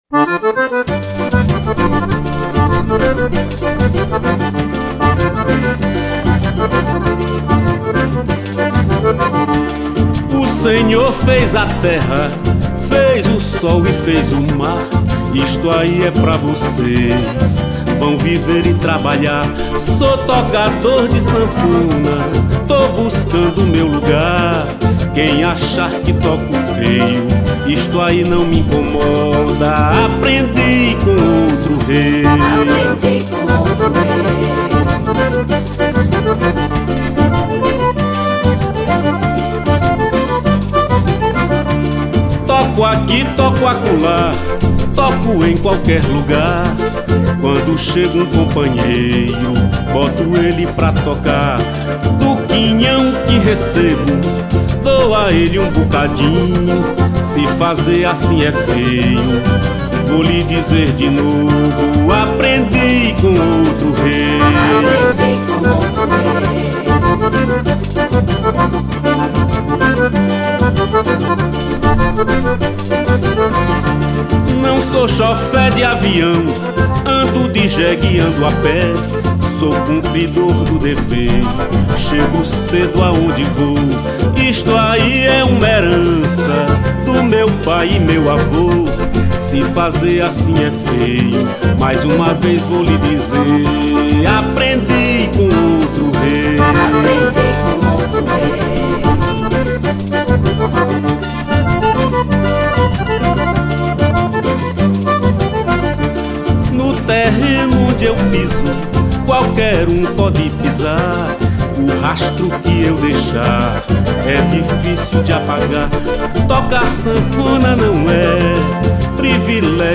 1189      Faixa:     Xote